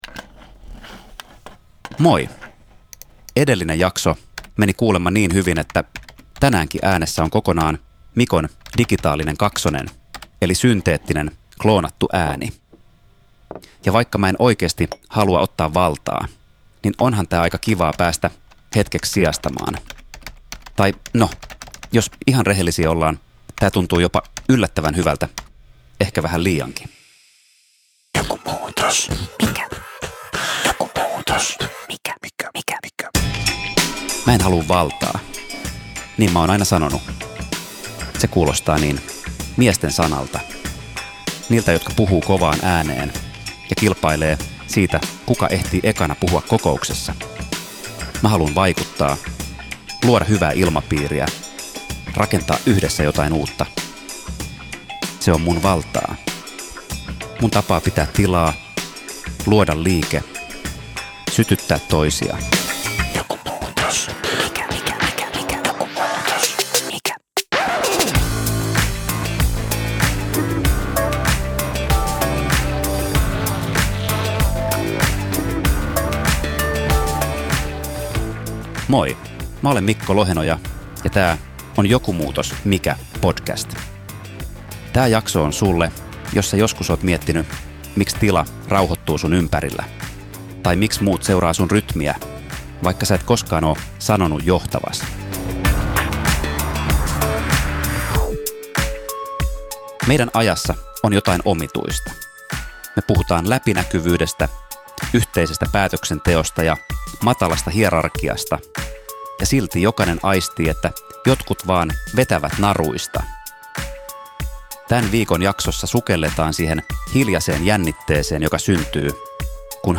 Tässä jaksossa puhutaan näkymättömästä vallasta, tilan lukemisesta ja siitä, miten johtajuus voi olla pehmeää, kehollista ja nostattavaa voimaa. Tässä jaksossa äänessä on tekijän oma ääniklooni, synteettisesti tuotettu versio hänen puheestaan.